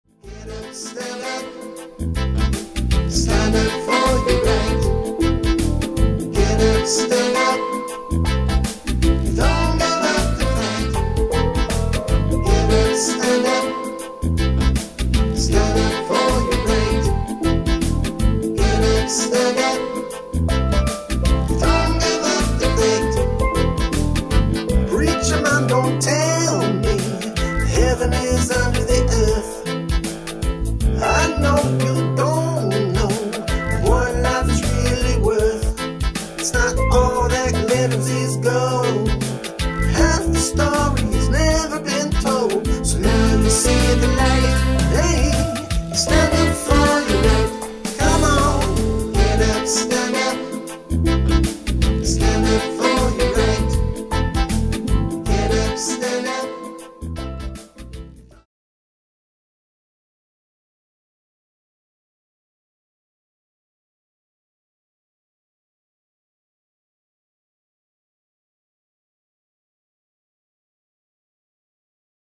radiates a laid-back beach party vibe
steel pan artistry